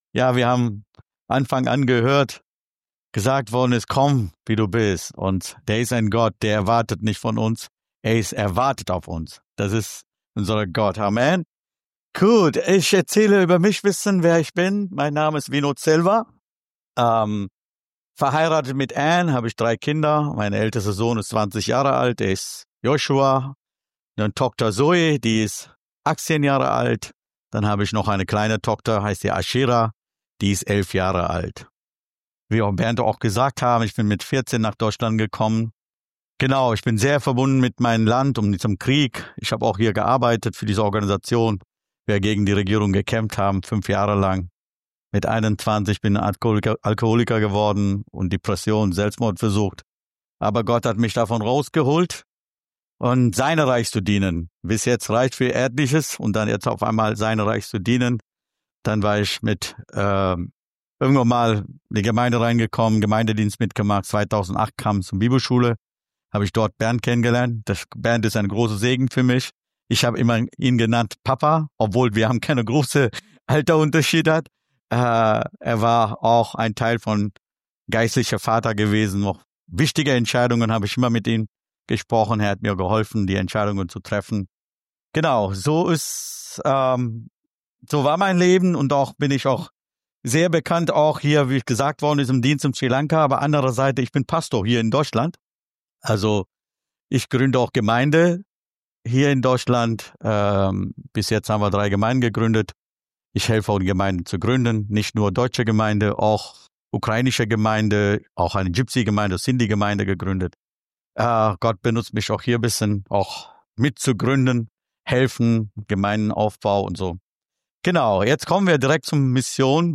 Predigten | FECG - Freie Evangelische Christus Gemeinde Ratzeburg